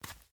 Minecraft Version Minecraft Version 1.21.5 Latest Release | Latest Snapshot 1.21.5 / assets / minecraft / sounds / mob / turtle / baby / shamble3.ogg Compare With Compare With Latest Release | Latest Snapshot
shamble3.ogg